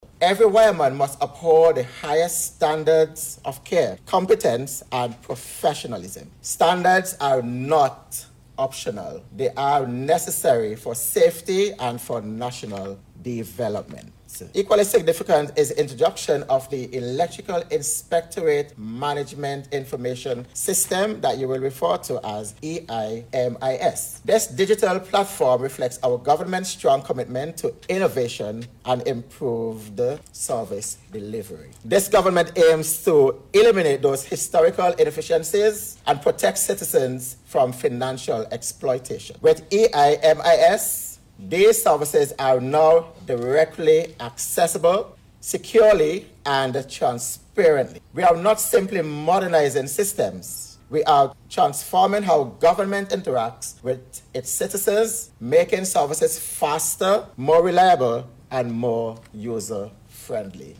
Minister Stephenson was delivering remarks at the official launch of the new online platform called the Electrical Inspectorate Management Information System (EIMIS)